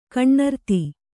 ♪ kaṇṇarti